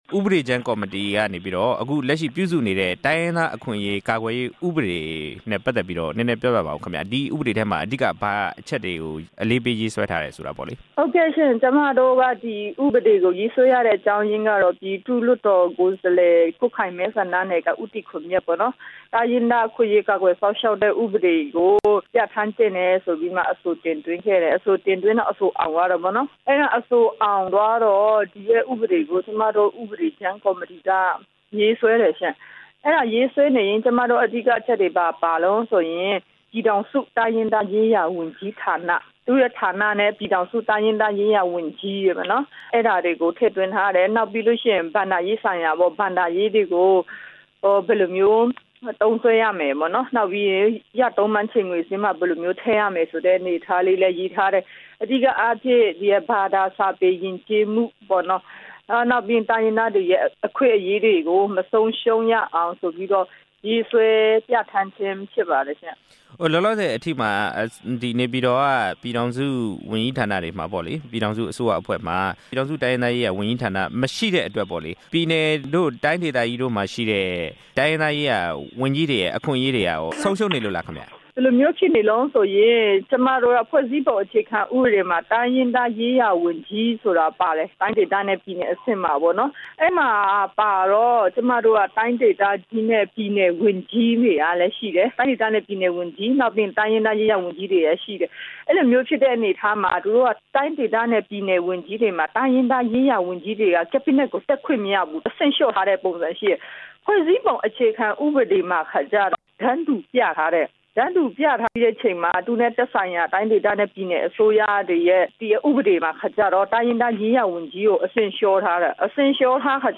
တိုင်းရင်သား အခွင့်အရေး ကာကွယ်စောင့်ရှောက်ရေး ဥပဒေမူကြမ်းနဲ့ ပတ်သက်တဲ့ ဆက်သွယ်မေးမြန်းချက်